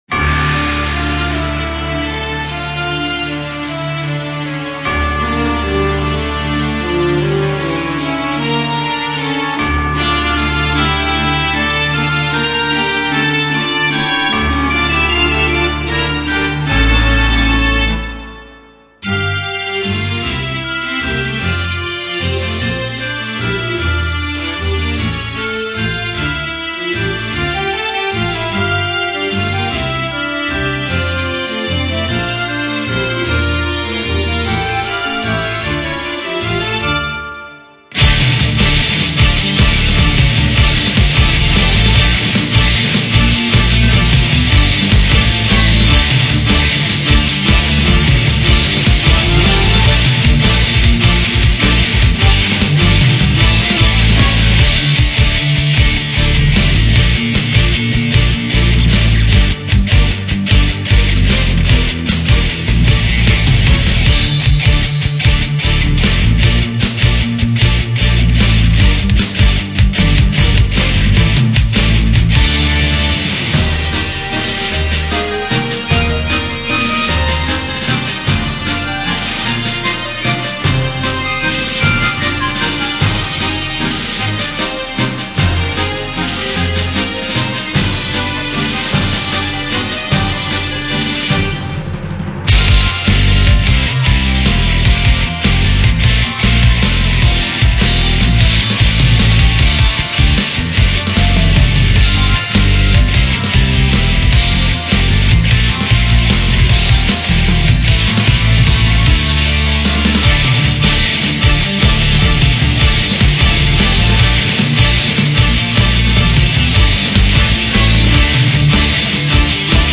Одна мелодия, без голоса.